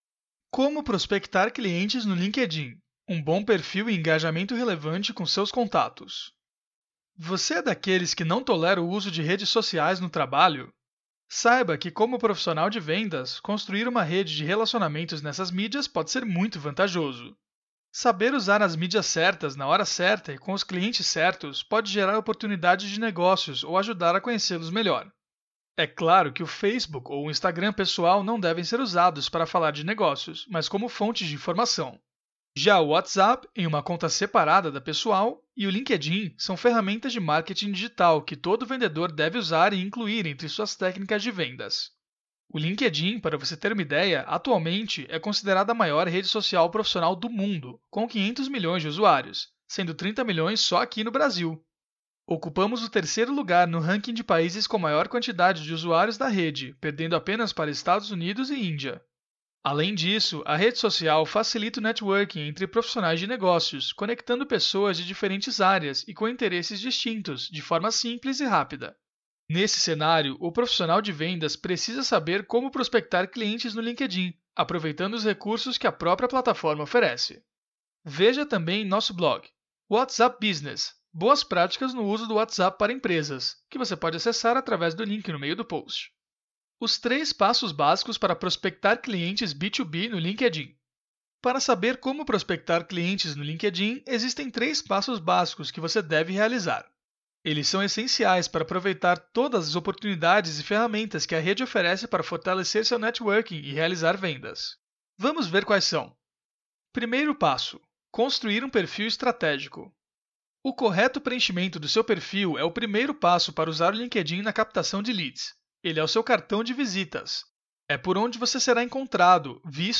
Aula 02
audio-aula-como-prospectar-clientes-no-linkedin.mp3